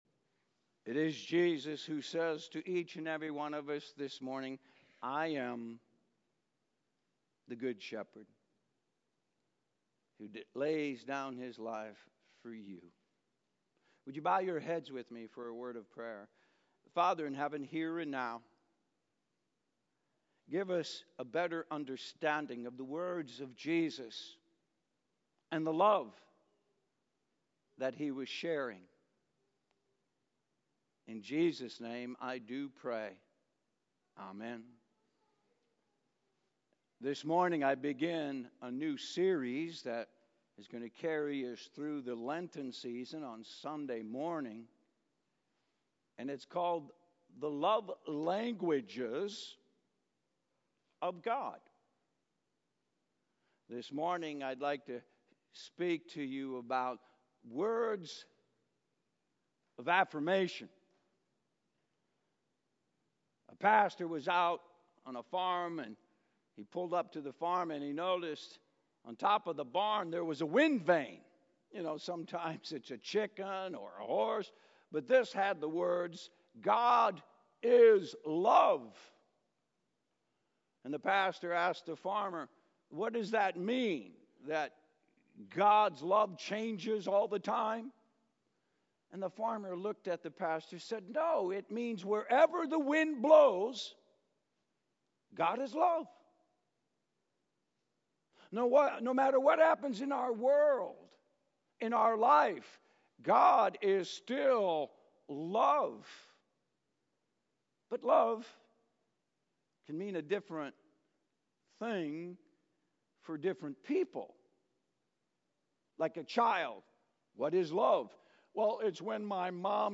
A message from the series "HIStory in 2024." Today we see that because a king could not sleep one night, he had the chronicles of his kingdom read aloud to him.